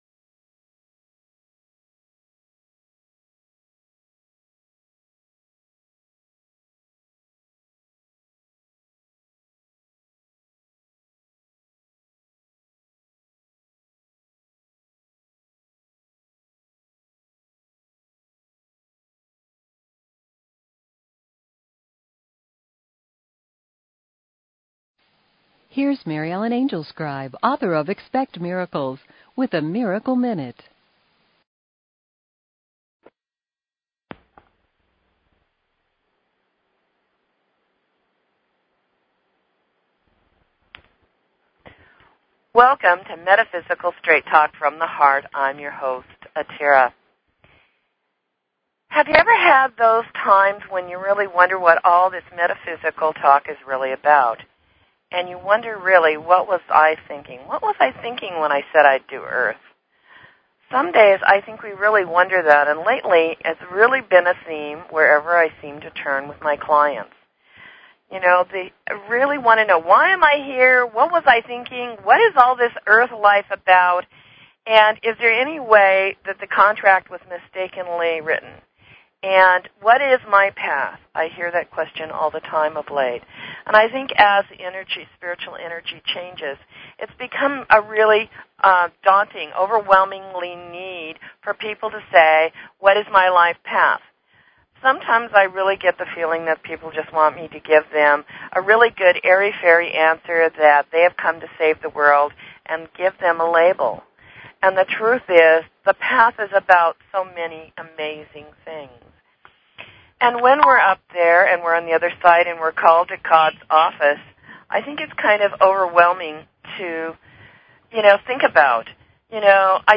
Talk Show Episode, Audio Podcast, Metaphysical_Straight_Talk and Courtesy of BBS Radio on , show guests , about , categorized as